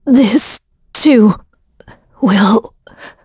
Isolet-Vox_Dead.wav